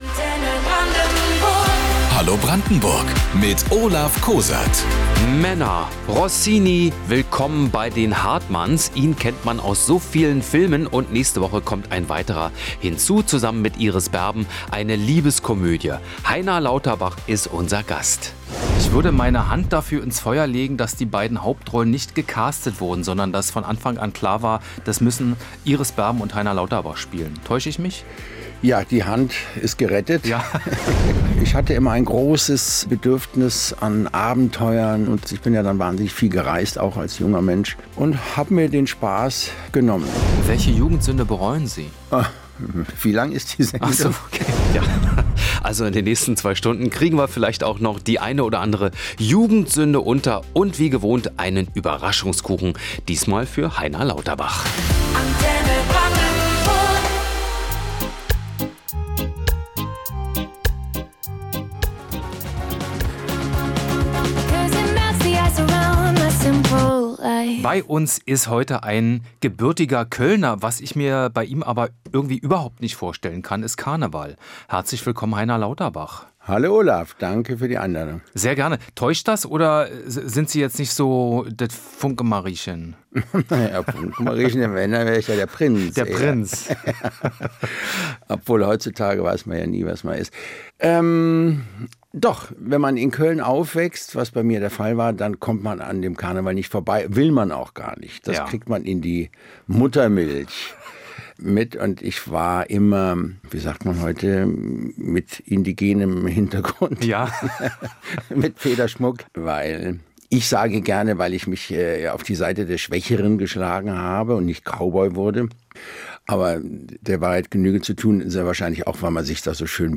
Gemeinsam mit Iris Berben ist er demnächst in einem neuen Kino-Film zu sehen: Heiner Lauterbach. Er war am Sonntag unser Gast.